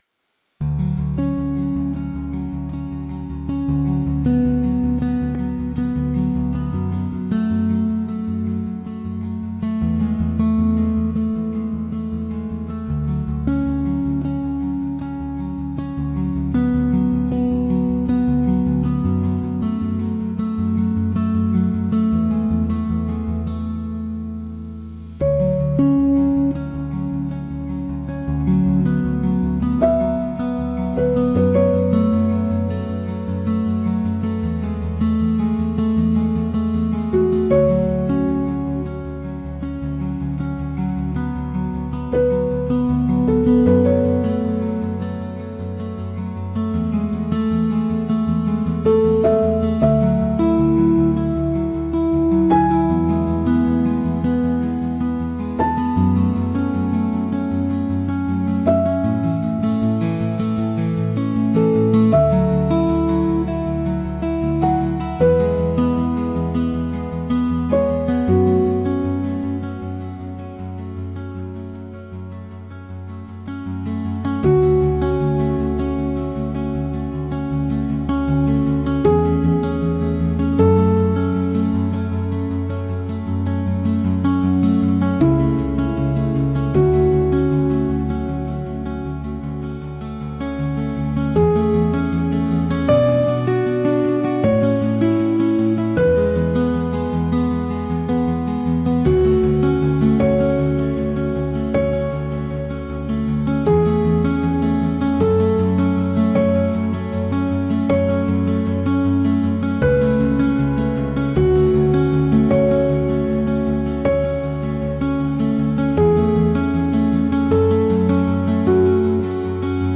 Instrumental: (Remastered)